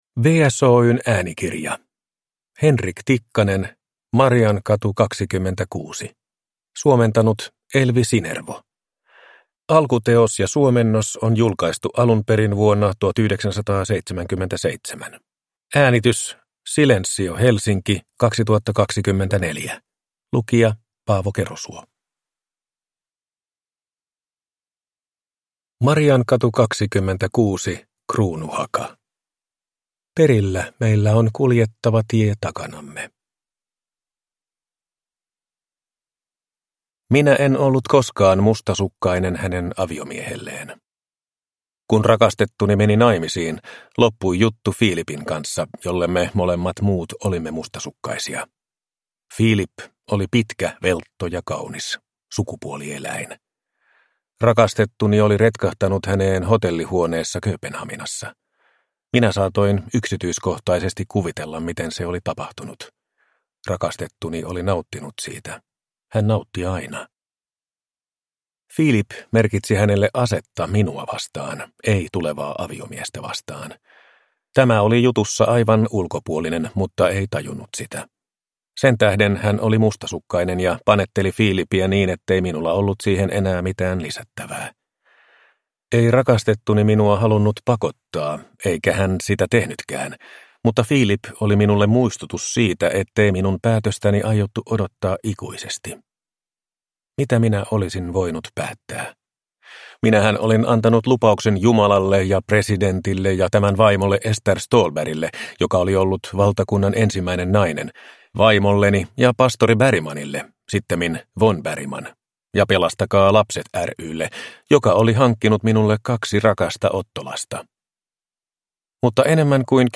Mariankatu 26 – Ljudbok